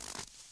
added base steps sounds
snow_0.ogg